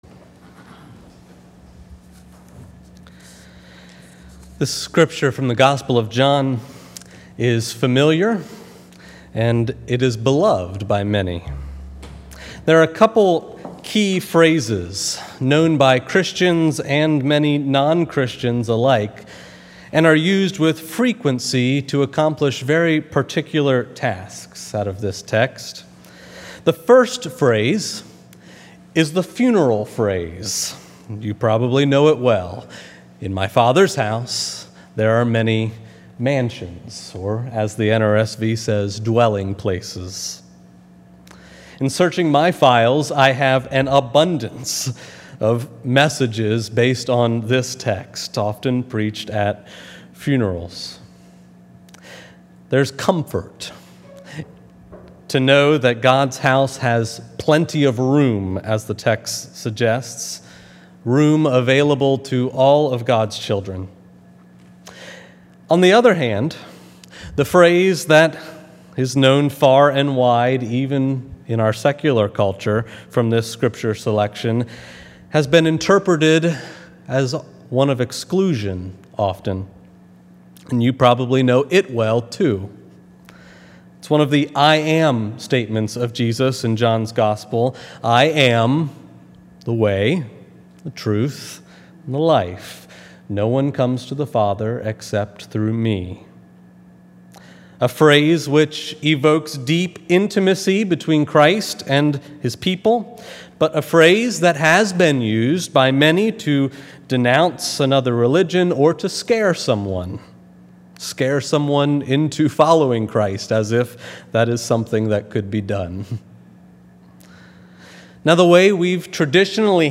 Service Type: Sunday Sermon